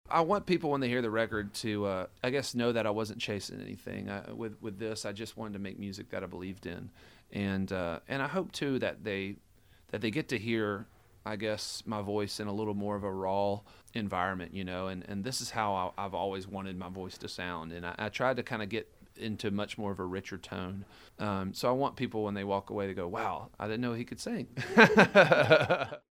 Audio / Charles Kelley talks about what he wants fans to go away with when they pick up his new solo album.
Charles Kelley (what he wants fans to go away with) OC: …could sing. [laughs] :26